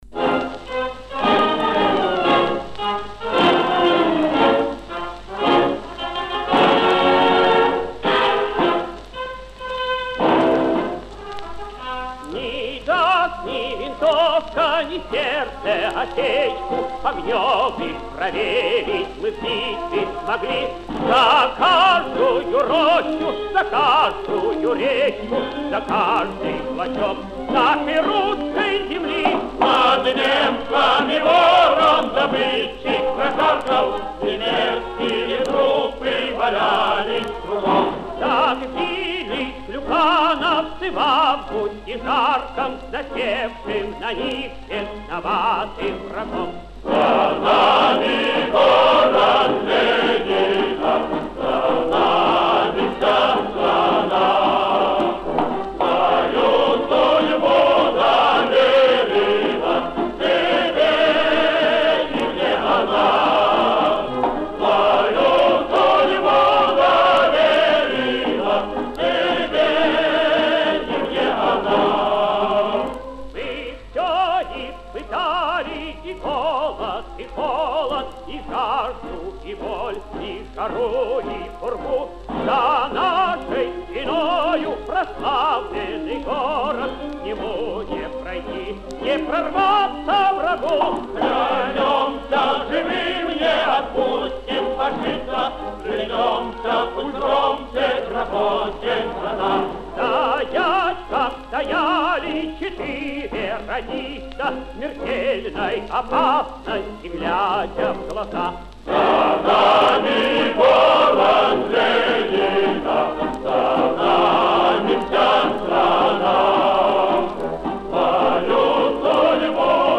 Повышение качества.